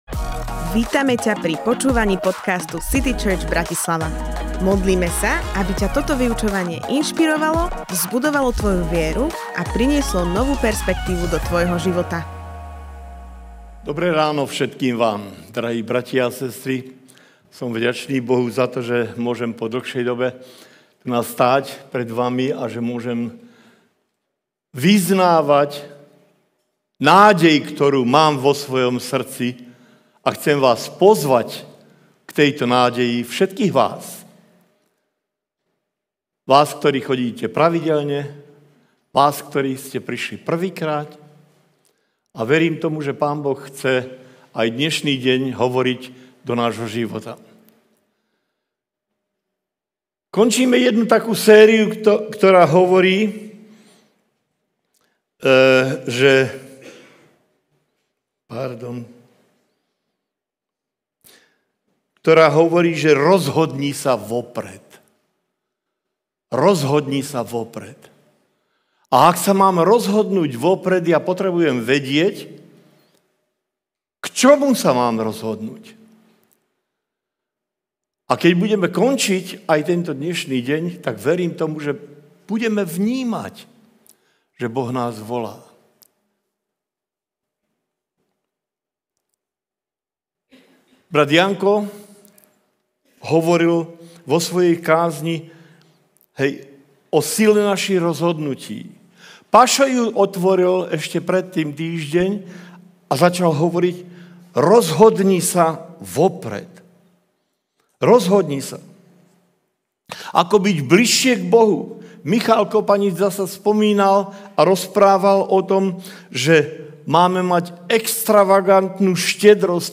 Kázeň týždňa